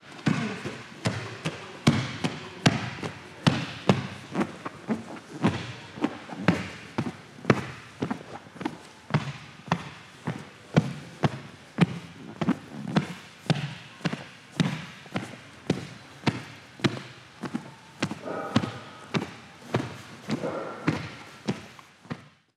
Andar sobre un tatami